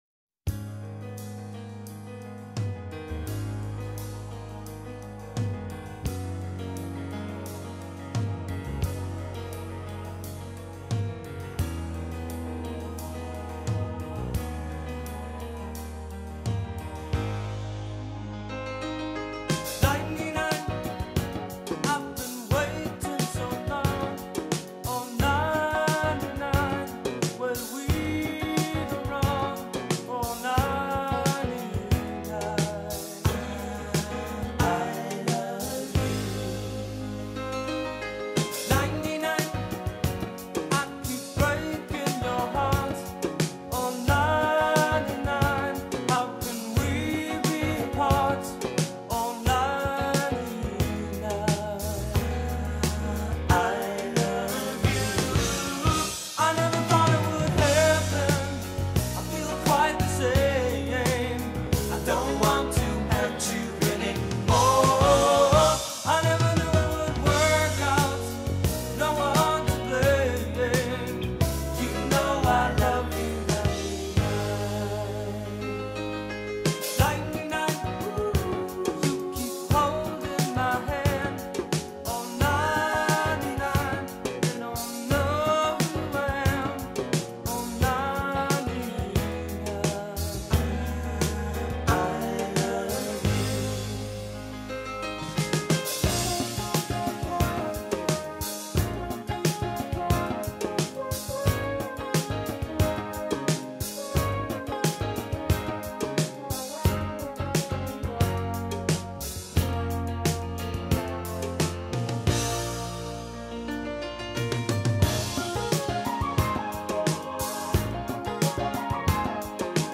Progressive Rock, Jazz Rock